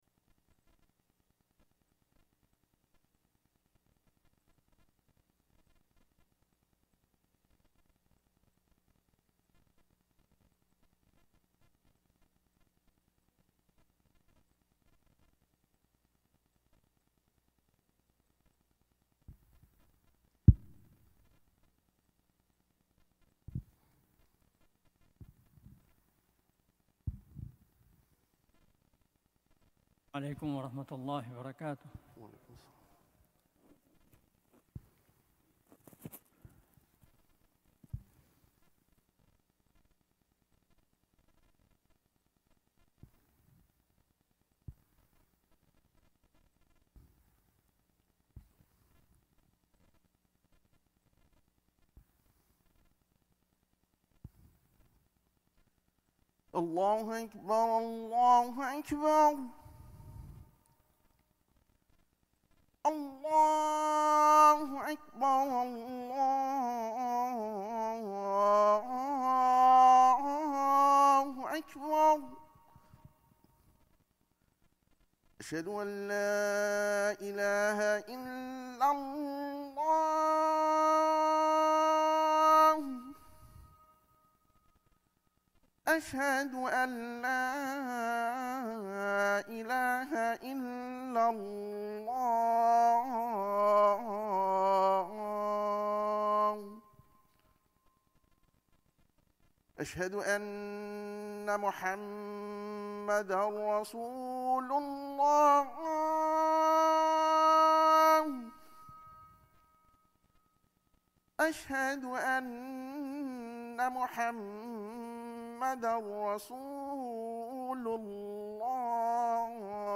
Friday Khutbah - "The Greatest Honor"